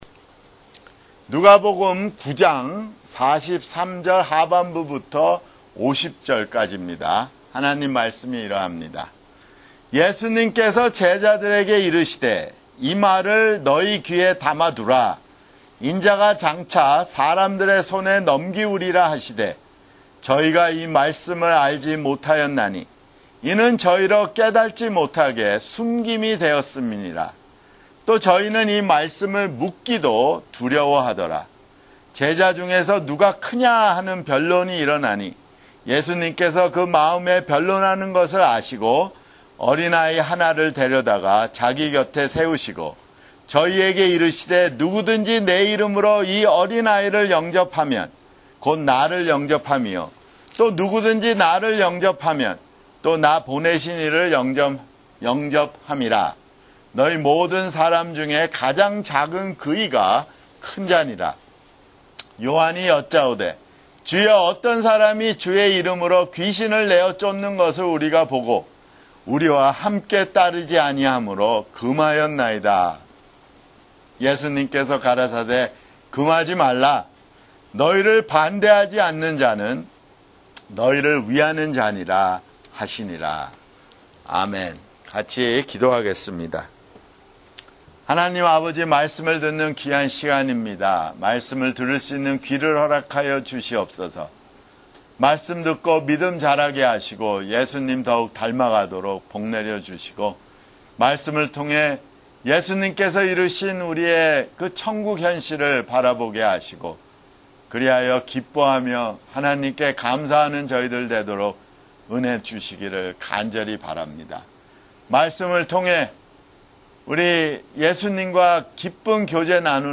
[주일설교] 누가복음 (67)